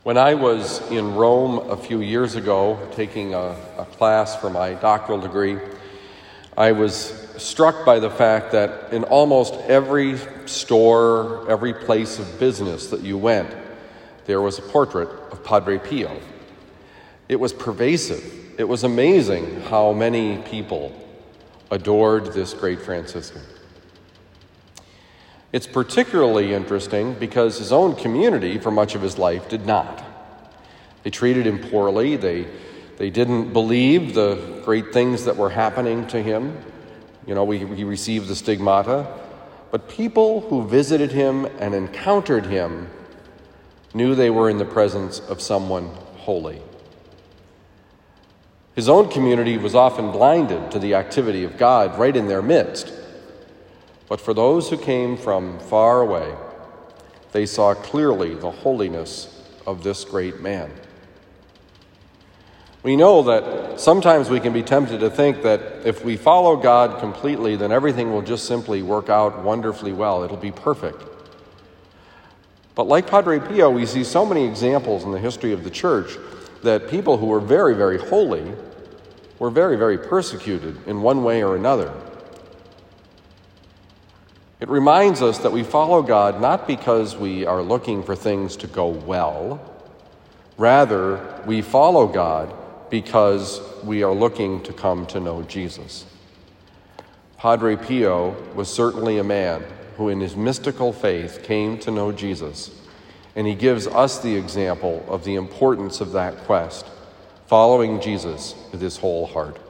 Homily for Thursday, September 23, 2021
Given at Christian Brothers College High School, Town and Country, Missouri.